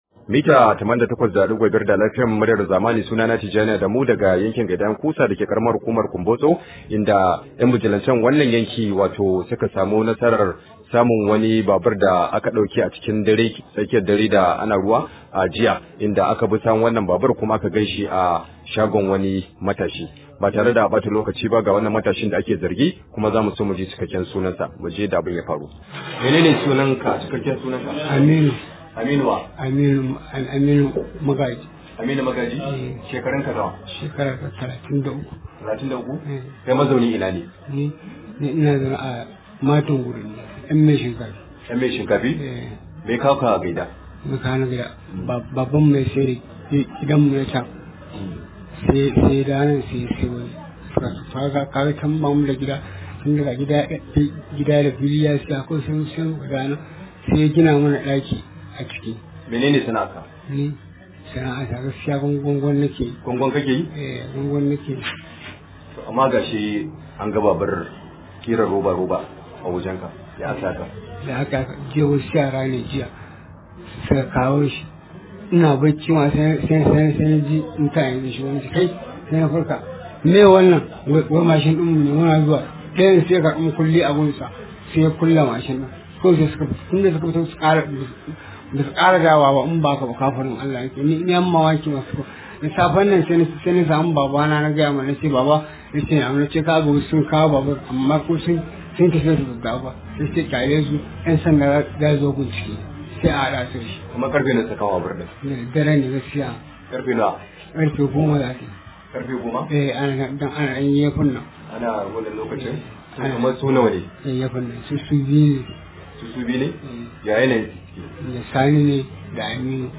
Rahoto: An tsinci Babur din da a ka sace a shagon wani matashi